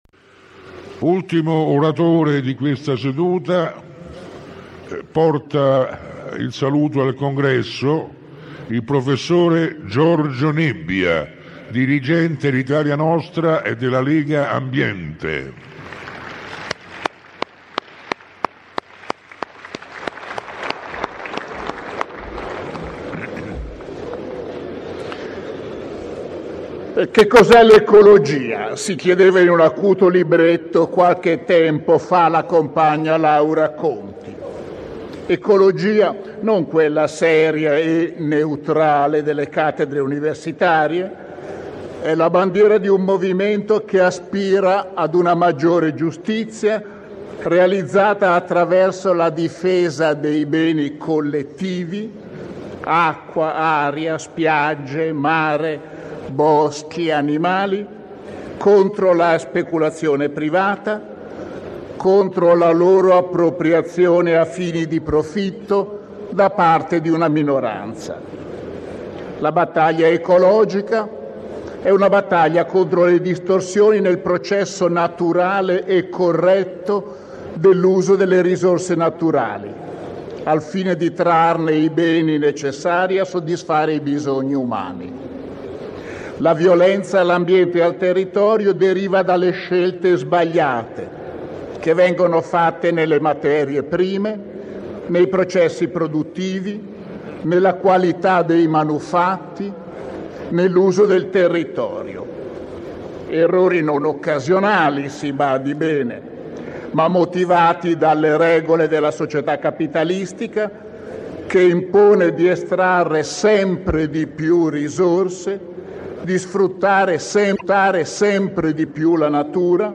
Nebbia-XVI-congresso_lowres.mp3